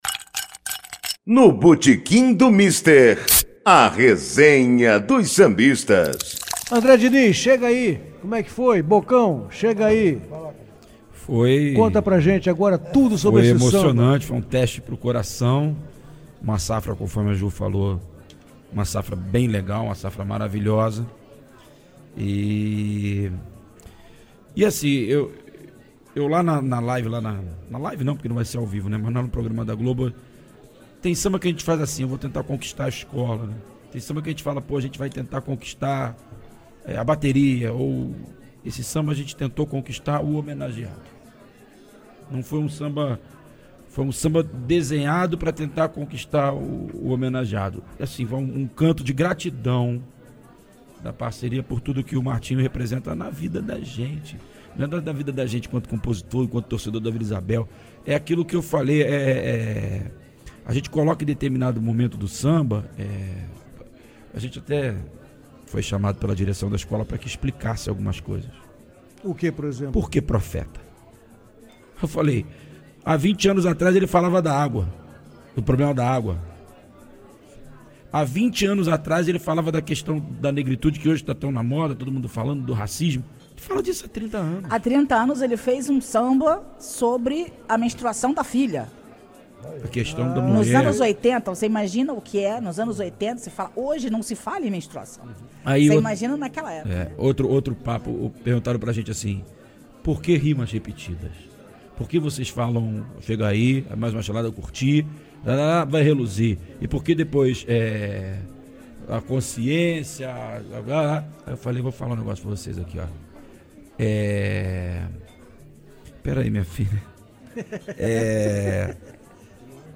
Presidente de honra da Unidos de Vila Isabel, o cantor e compositor Martinho da Vila foi entrevistado pelo programa, e, além de elogiar o samba, cantou o trecho da obra que considera mais forte.